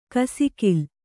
♪ kasikil